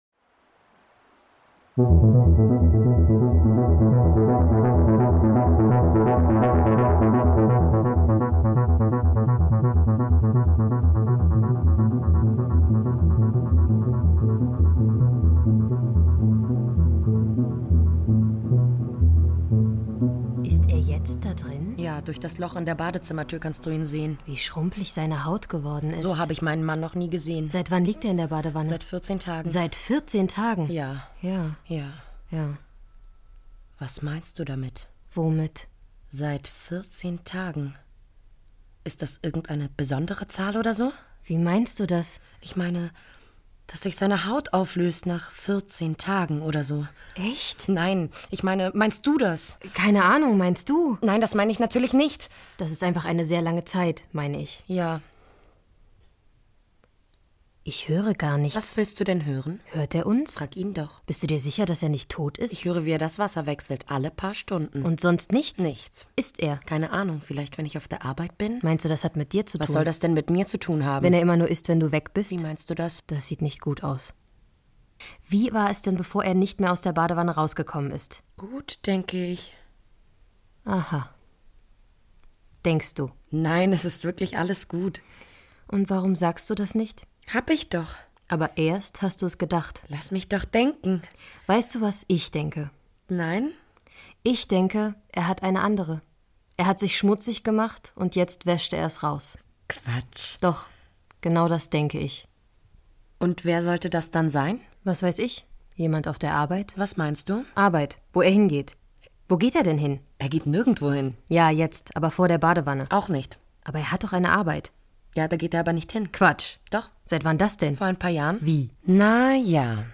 24.10.2018 SWR Hörspiele Die Shortlist des ARD PiNball 2018 - Hörspiel Nr. 5: Die weite weite Sofalandschaft Seit zwei Wochen liegt er in der Wanne, denn draußen warten das Home Office und zwei Frauen.